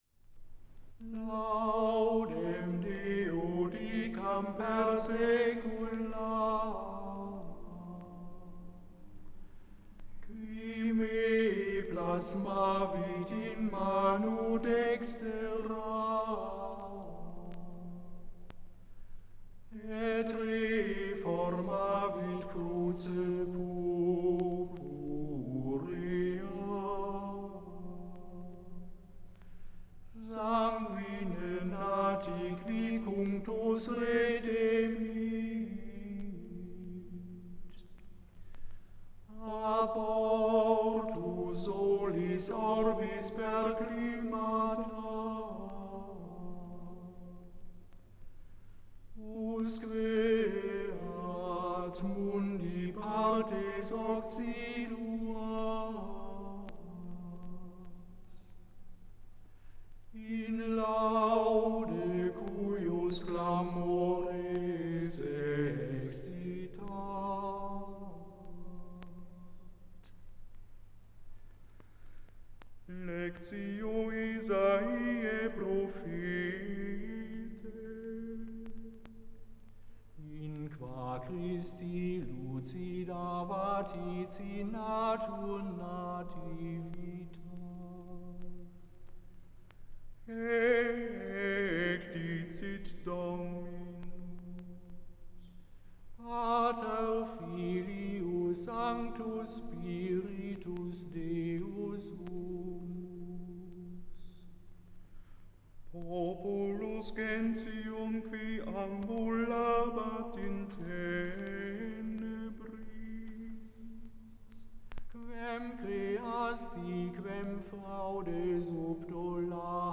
aufgenommen in der Klosterkirche Knechtsteden